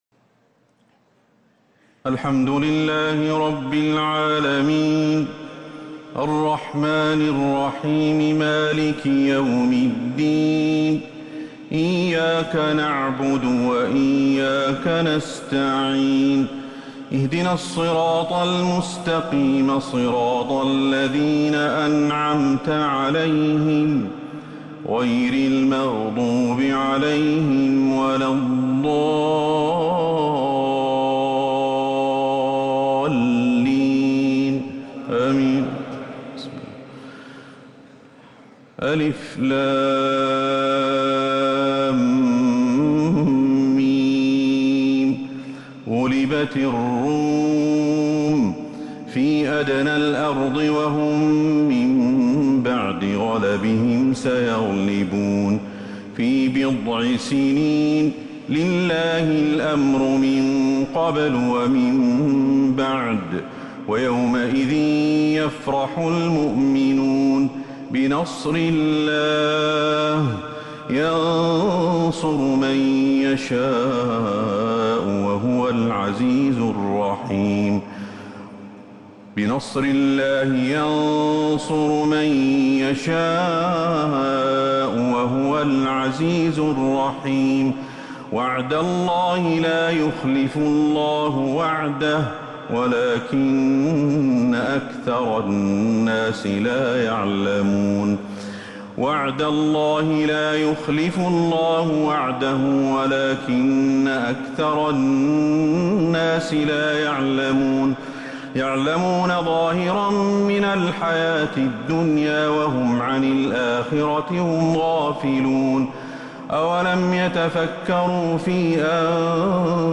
تهجد ليلة 23 رمضان 1447هـ فواتح سورة الروم (1-32) | Tahajjud 23rd night Ramadan 1447H Surah Ar-Room > تراويح الحرم النبوي عام 1447 🕌 > التراويح - تلاوات الحرمين